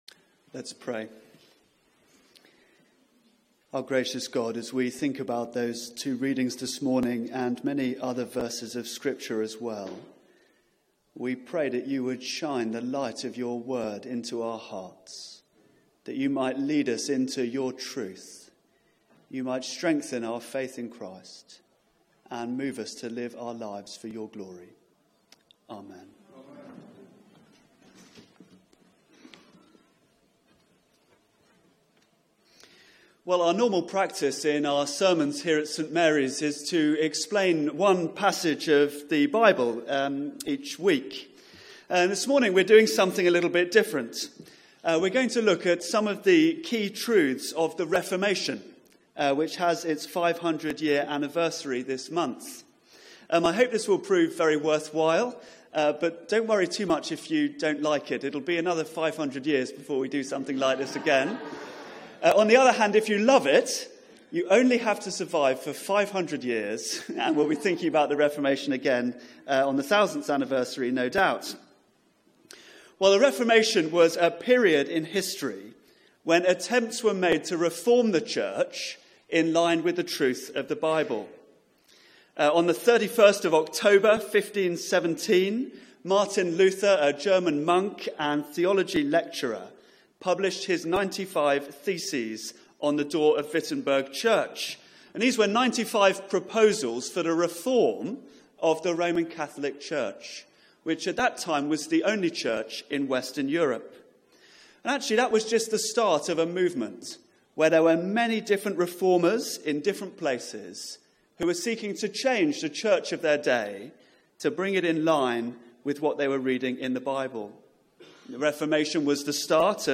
Media for 9:15am Service on Sun 29th Oct 2017 09:15
Sermon